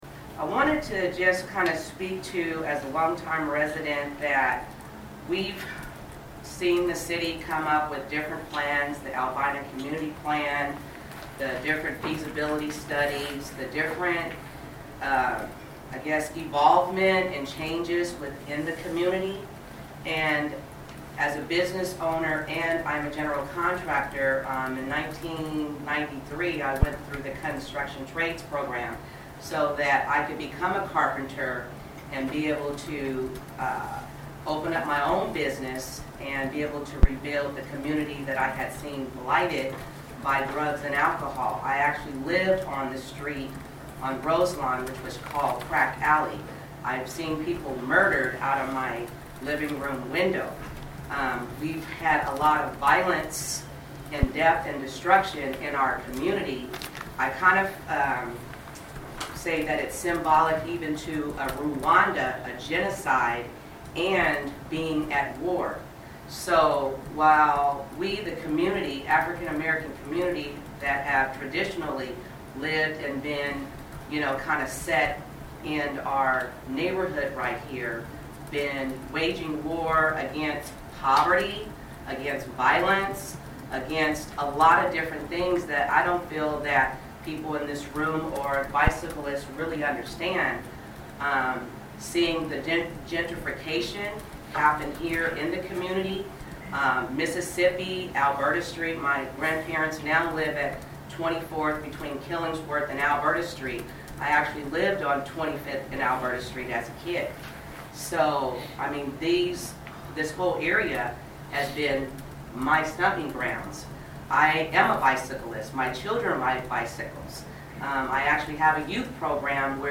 At the monthly meeting of the North Williams Traffic Safety Operations Project Stakeholders Advisory Committee Meeting today, PBOT and project consultants announced that decisions about how to move forward with the project will be delayed until at least this fall.